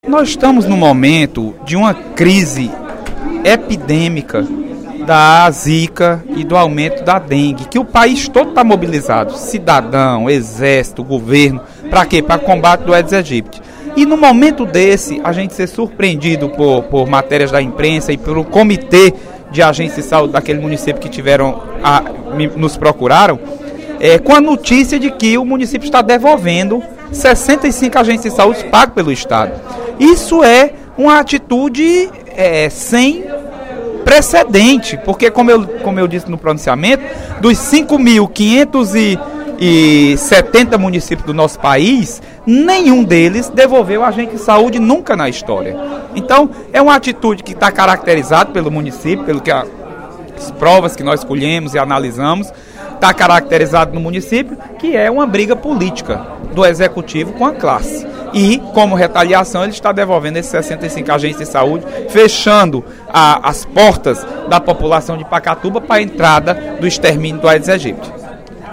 O deputado Leonardo Araújo (PMDB) fez pronunciamento nesta quinta-feira (25/02), durante o primeiro expediente da Assembleia Legislativa, para criticar a devolução de 65 agentes de saúde do município de Pacatuba para o Estado.